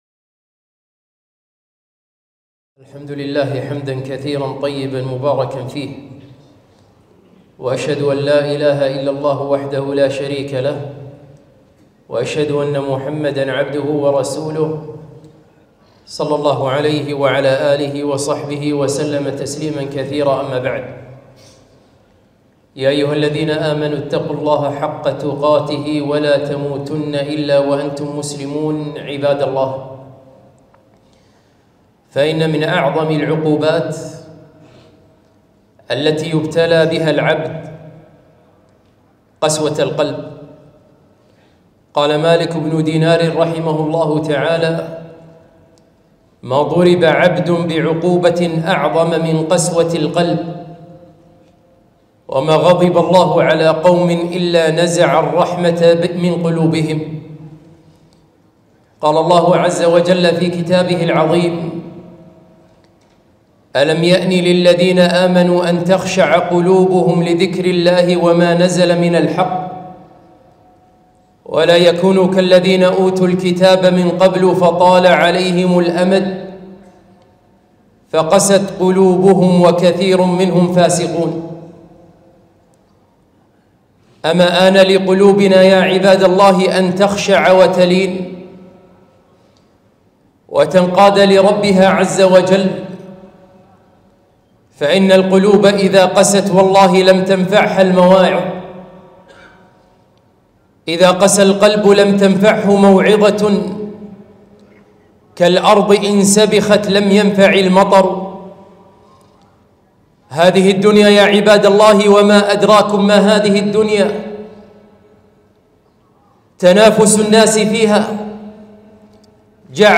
خطبة - ما أقسى القلوب؟!